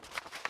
x_enchanting_scroll.3.ogg